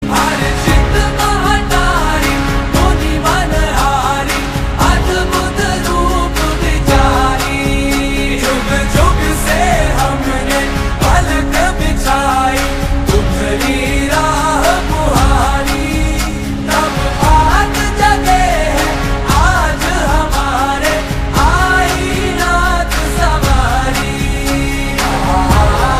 Hindi Songs
soul-stirring melody
a harmonious blend of devotion and melody